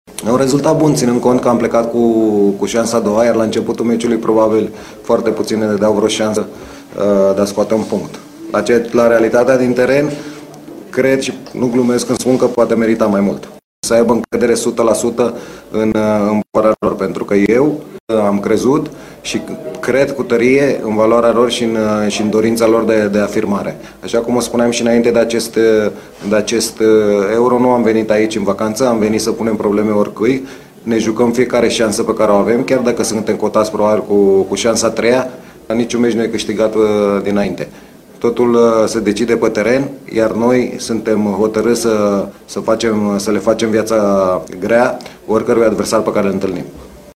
Mijlocaşul formaţiei FC Viitorul a avut o execuție senzațională, din lovitură liberă, exprimându-și bucuria că a făcut totată țara fericită:
Selecţionerul Adrian Mutu, a declarat, la rândul său, că România ar fi meritat să câştige cele trei puncte ţinând cont de numărul de ocazii: